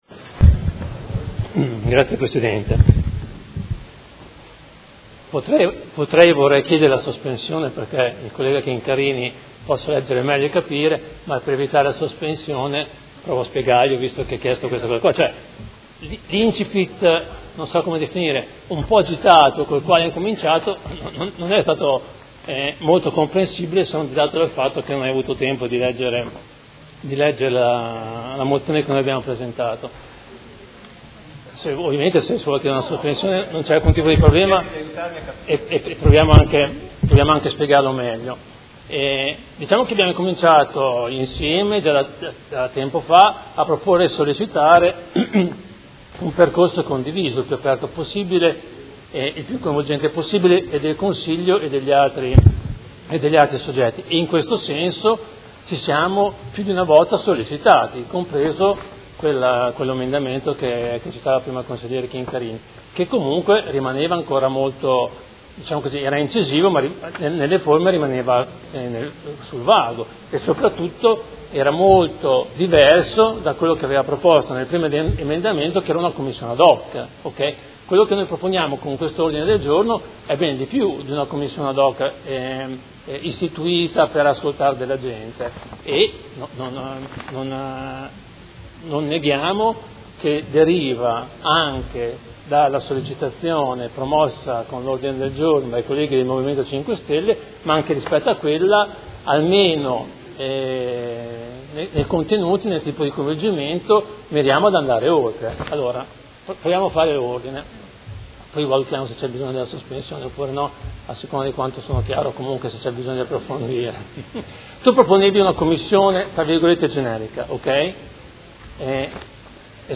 Fabio Poggi — Sito Audio Consiglio Comunale
Seduta del 26/06/2017. Dibattito su Ordine del Giorno presentato dal Movimento cinque Stelle avente per oggetto: Partecipazione di associazioni alla Conferenza dei Servizi per il progetto di recupero e riqualificazione dell’ex Sant’Agostino e Ordine del Giorno presentato dai consiglieri Baracchi, Pacchioni, Arletti, Bortolamasi e Venturelli (P.D.), Stella e Rocco (Art.1-MPD) avente per oggetto: Conferenza preliminare del procedimento accordo di programma per la riqualificazione urbana del complesso dell'ex-ospedale Sant'Agostino